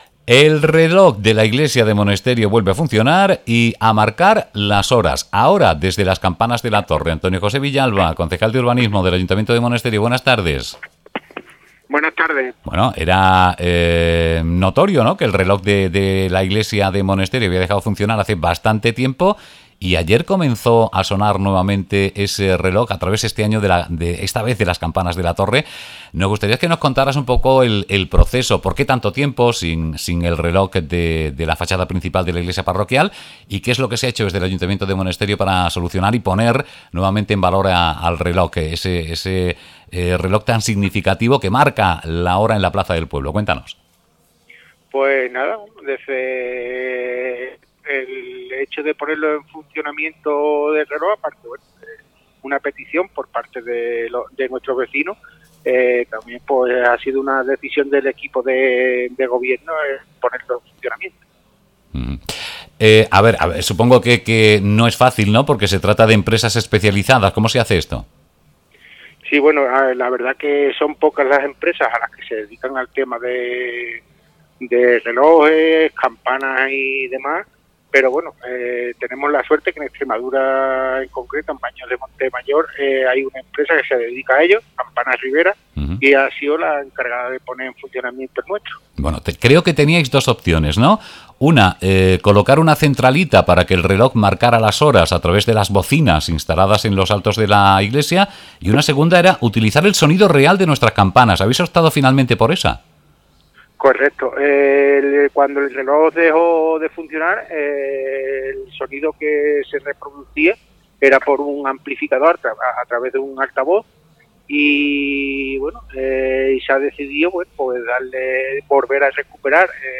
El reloj de la iglesia de Monesterio vuelve a marcar las horas y recupera el sonido de su campana
cti5BCAMPANAS.mp3